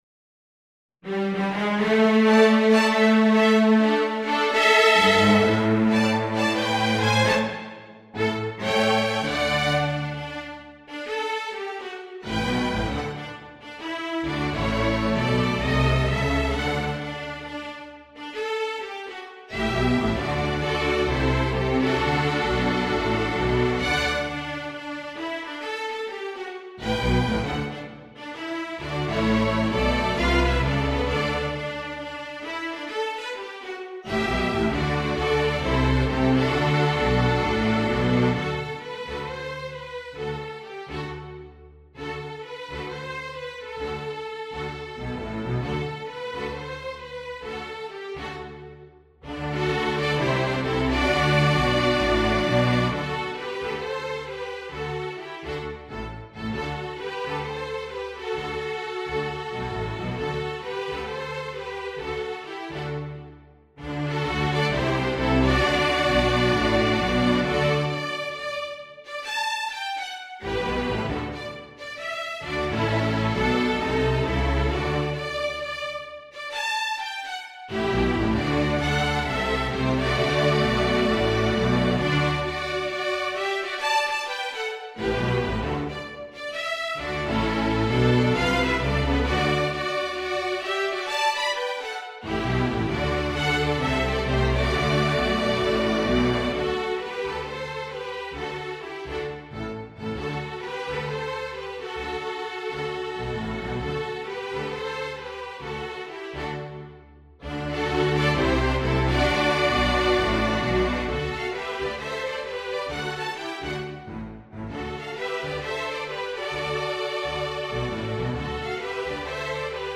A jazzy, swing edition of traditional American Folk tune
for String Quartet or String Orchestra
Folk and World